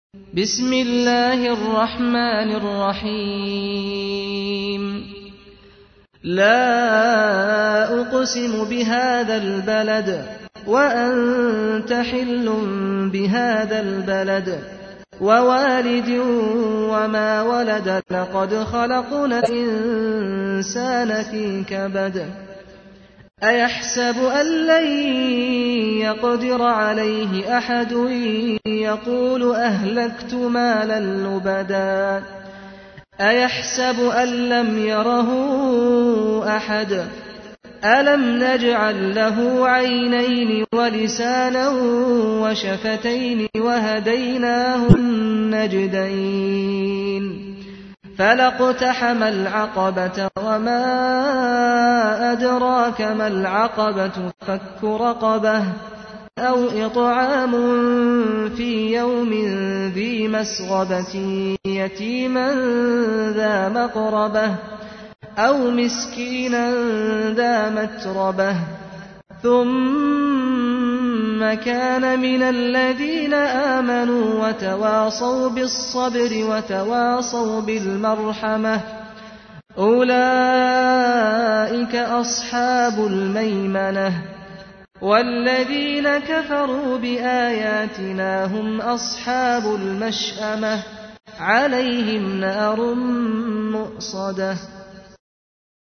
تحميل : 90. سورة البلد / القارئ سعد الغامدي / القرآن الكريم / موقع يا حسين